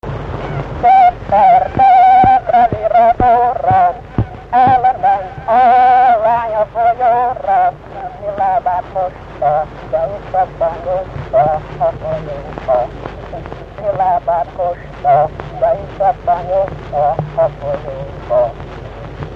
Erdély - Udvarhely vm. - Székelyudvarhely
ének
Stílus: 6. Duda-kanász mulattató stílus
Kadencia: 5 (5) b3 1